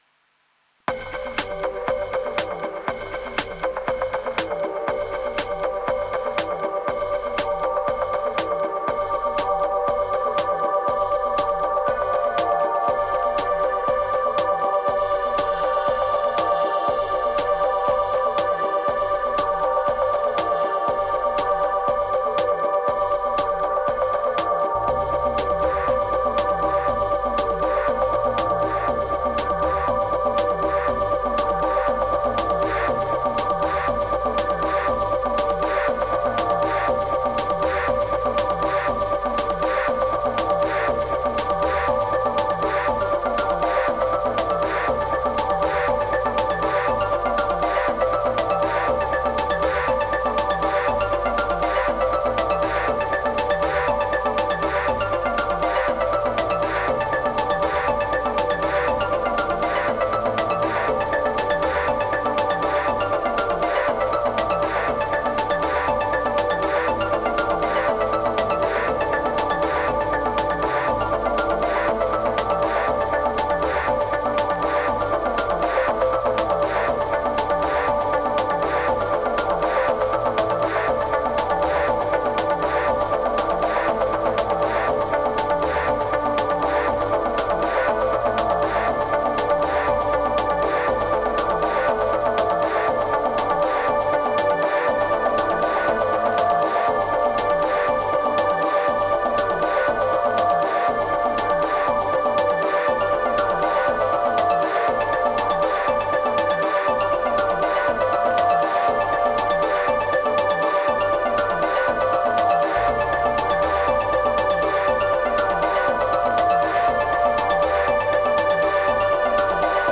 Ends up pretty velocious.